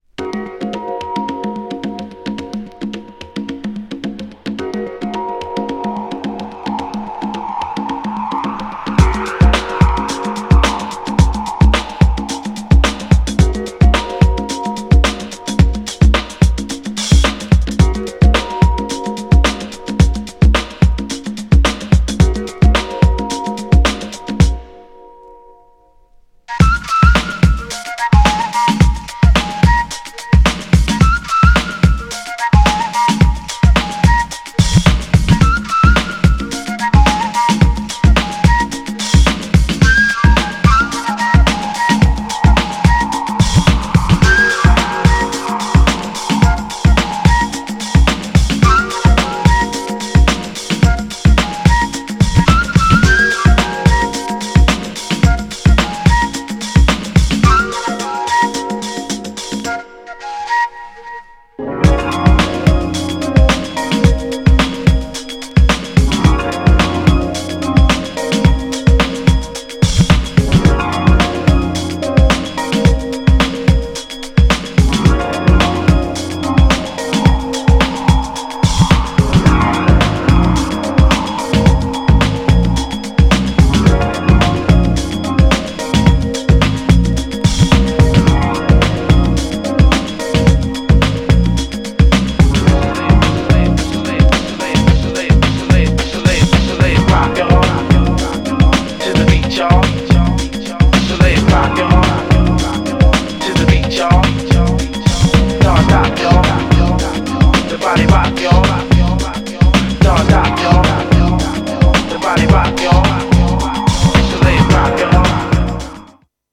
GENRE House
BPM 126〜130BPM